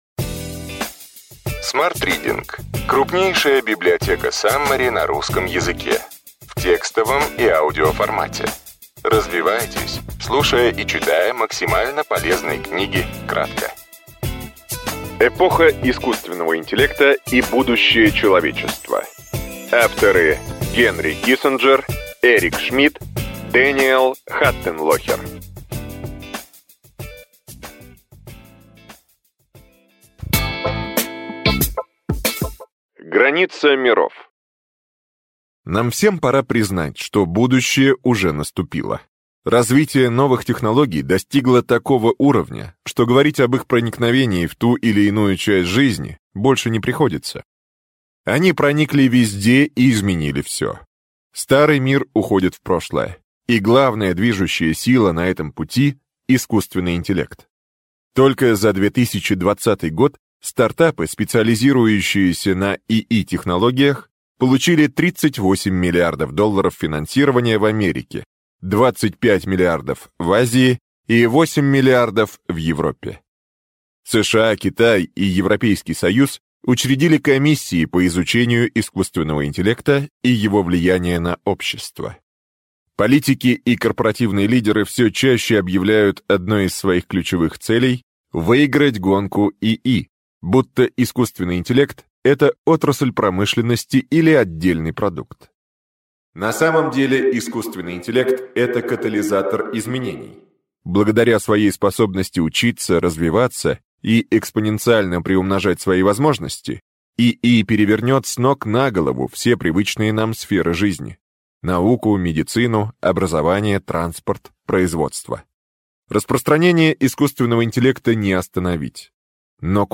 Аудиокнига Ключевые идеи книги: Эпоха искусственного интеллекта и будущее человечества.